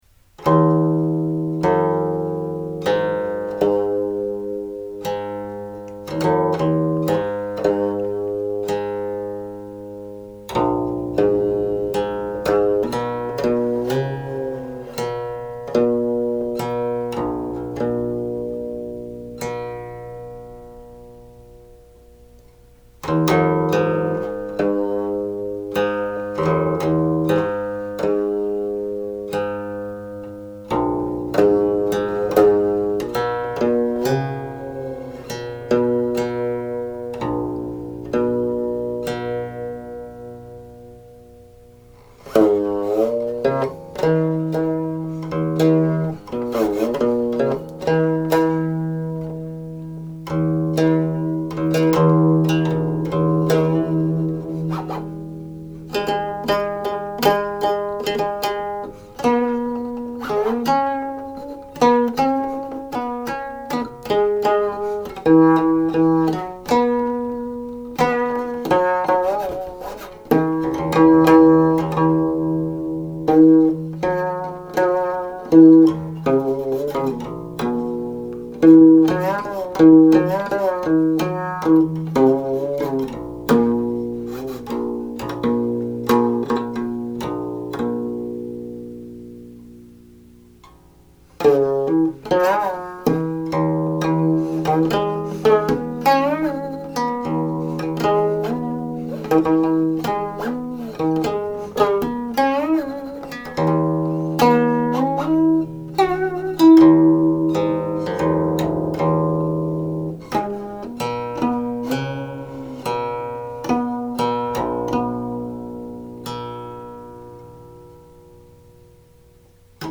The 1525 version is very melodic, with many musical couplets, the second part of the couple often an elaboration and/or extension of the first part.
Seven sections, titled16
4. 可教 Teachable (harmonics)
The "closing" stands out because the music changes somewhat here (encompassing four short phrases, in the original tablature it is simply the second part of the last section, leading up to the closing harmonics.)